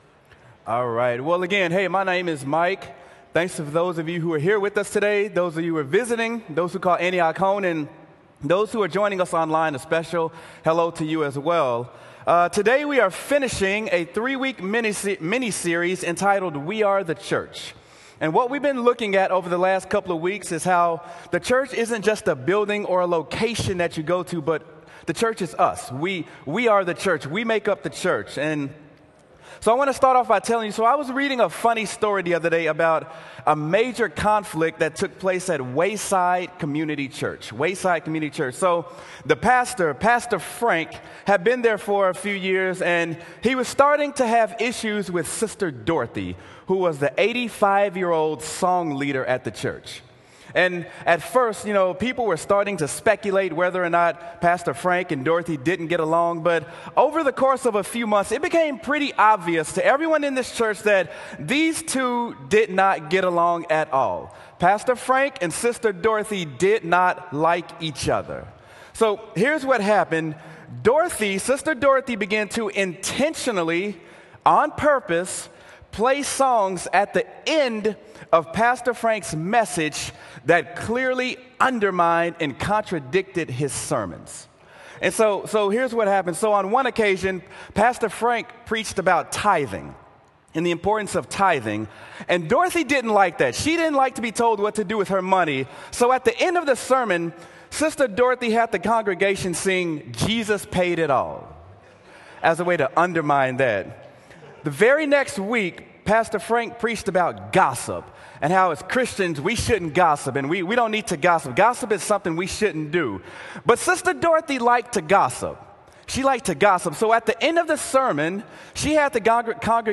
Sermon: Acts: We Are the Church: Conflict in the Church